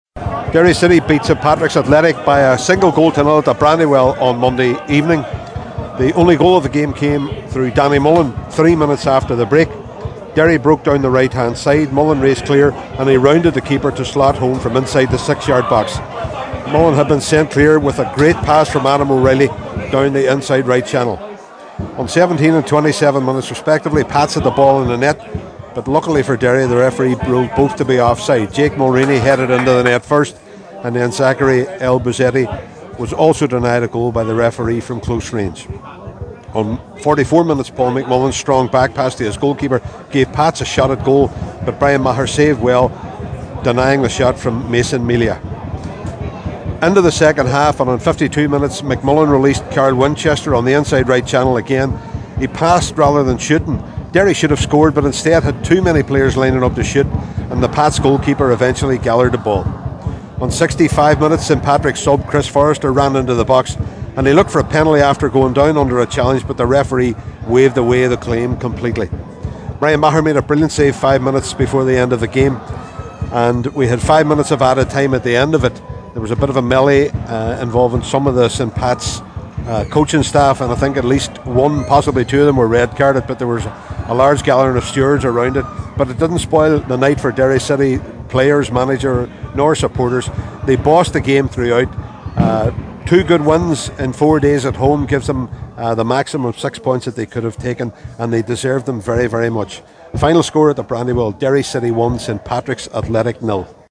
Derry-report-v-St-Patrick-s-Athletic.mp3